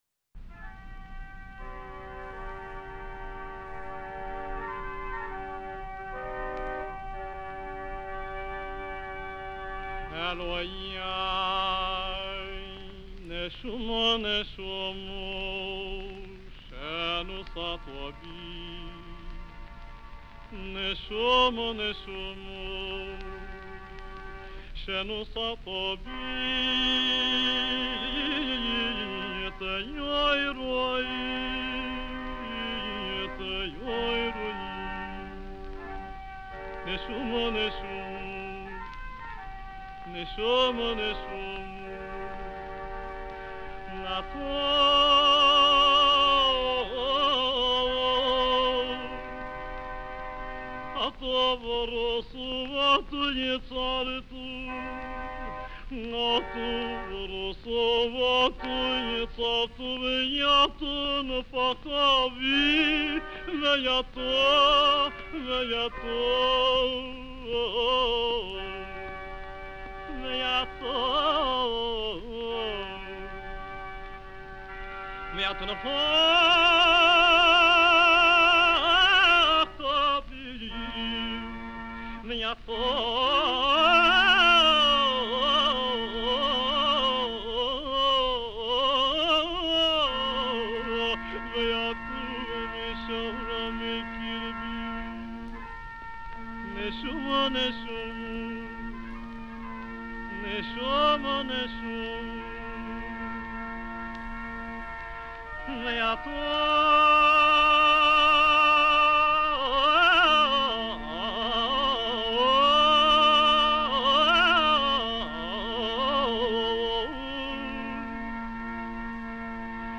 Pierre Pinchik sings
It didn't quite meet Pinchik's musical background, though: the congregation of that synagogue was accustomed to music in the austere German-Jewish taste, pretty different from Pinchik's Sephardic style rich of ornaments and emotional outbursts.